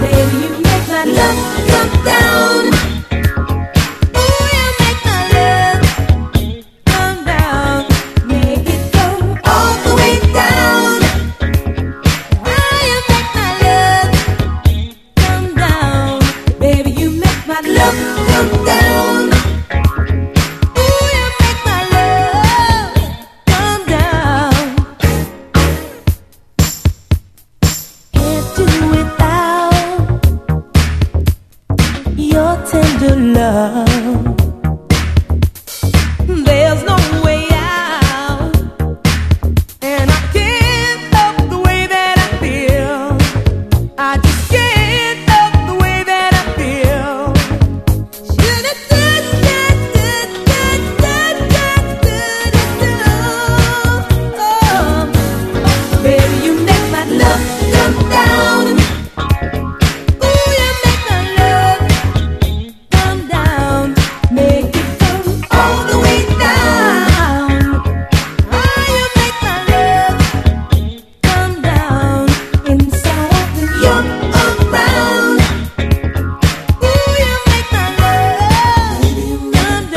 RHYTHM & BLUES
55年発表のフィメイルNEW BREED R&B！
ブリブリとサックスも唸りをあげる最高のパーティー・ユースなお転婆NEW BREED R&B！